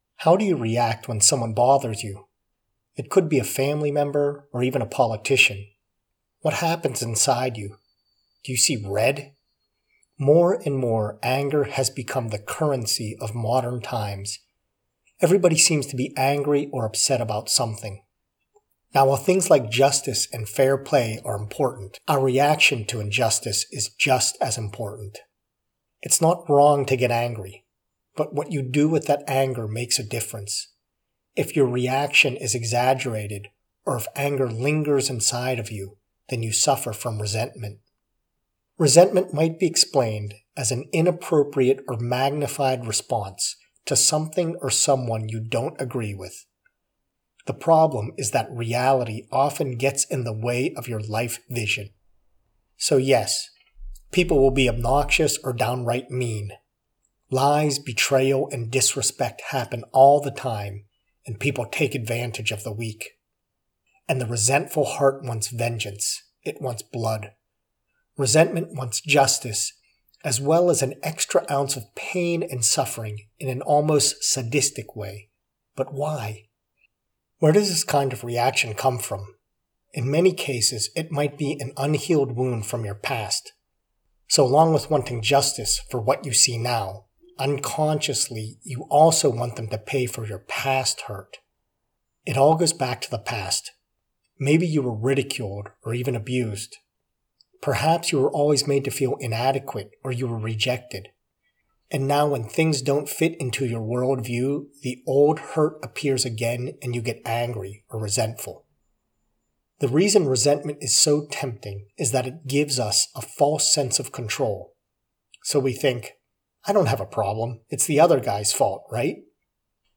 A message and prayer